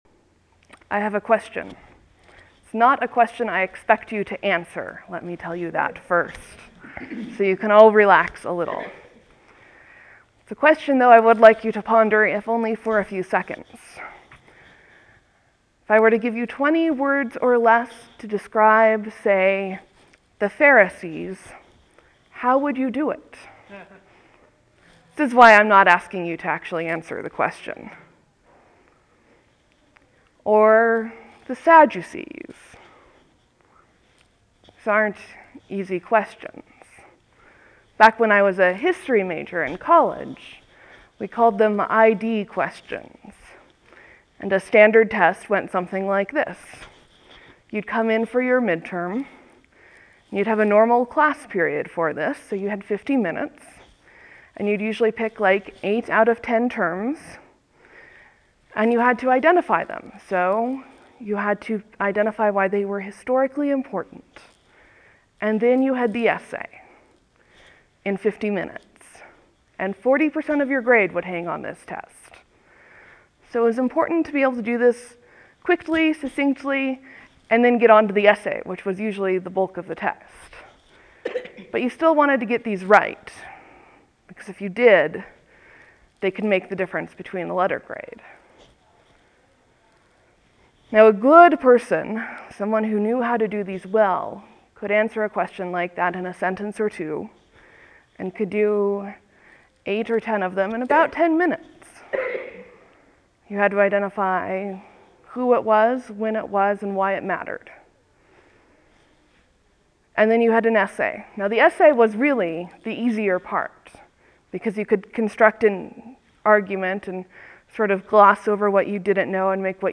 (There will be a few moments of silence before the sermon begins. Thank you for your patience.)